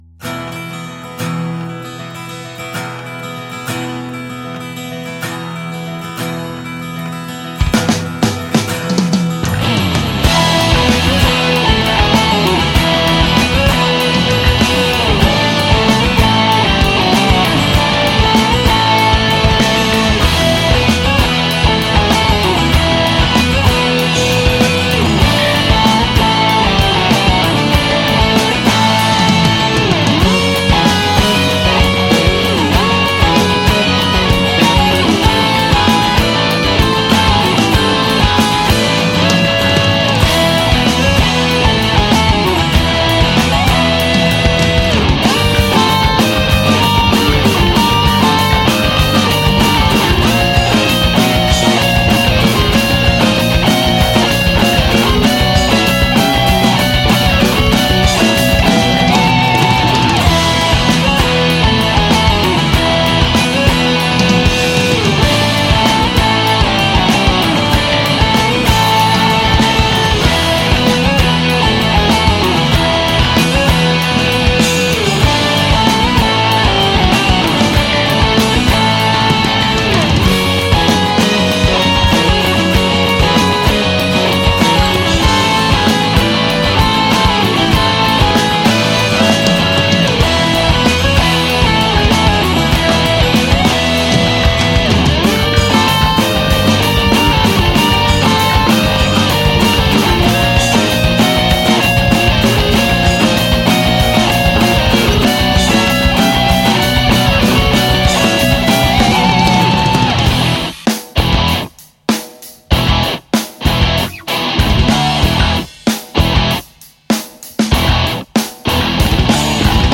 GenereRock